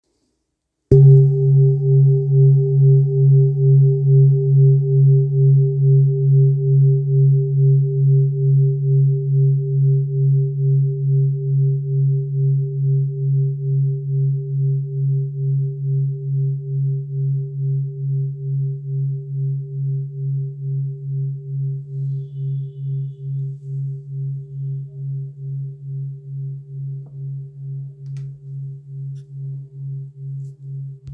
Kopre Singing Bowl, Buddhist Hand Beaten, Antique Finishing
Material Seven Bronze Metal